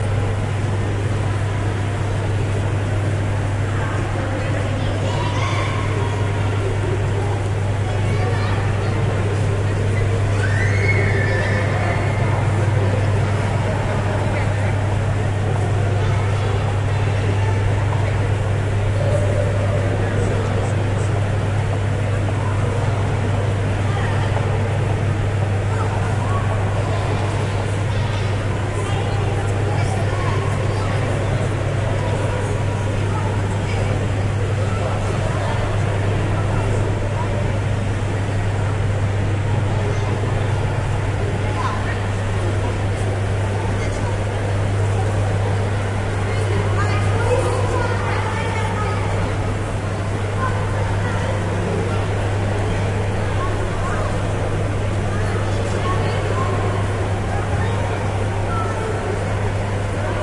描述：在伦敦泰特现代美术馆进行的现场录音，旁边是马塞尔杜尚的《被单身汉剥光的新娘》，甚至（大玻璃）191523，这里有一个图片：
标签： 艺术画廊 现场记录 画廊 环境 伦敦 马塞尔 - 杜尚 泰特现代
声道立体声